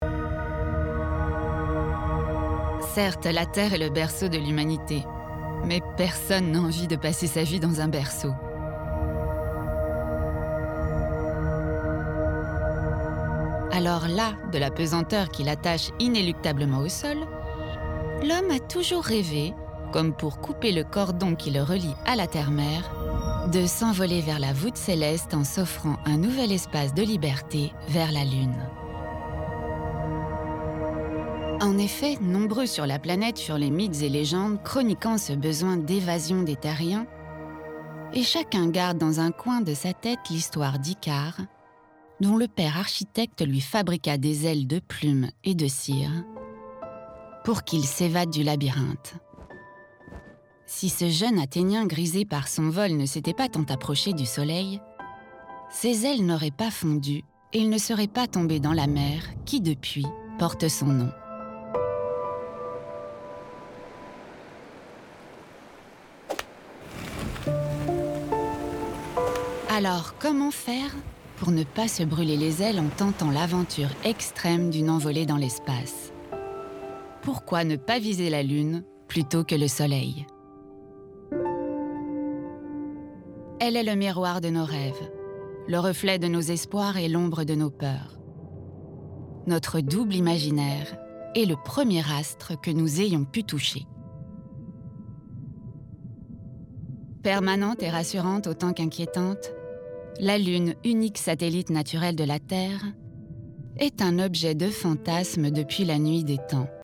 DOCUMENTAIRE (Il était une fois la lune) – adulte - medium - enjouée